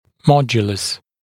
[‘mɔdjələs][‘модйэлэс]модуль, коэффициент